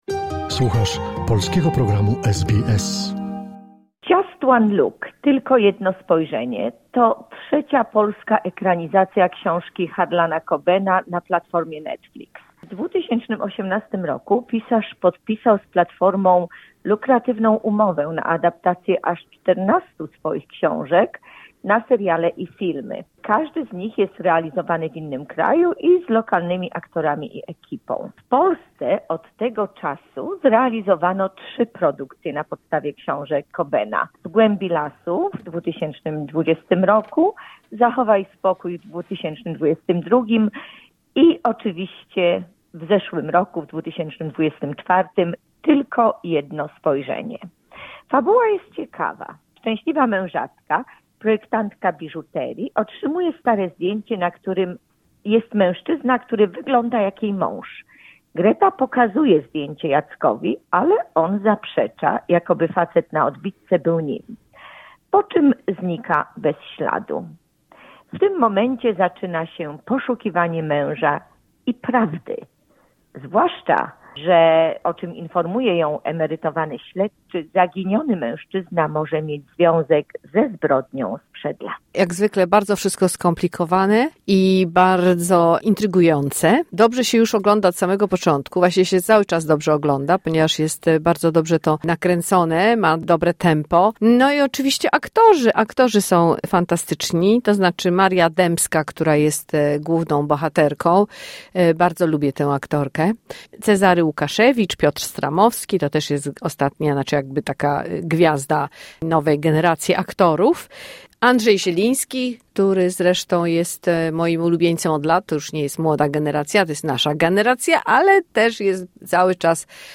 "Just one look" - recenzja filmowa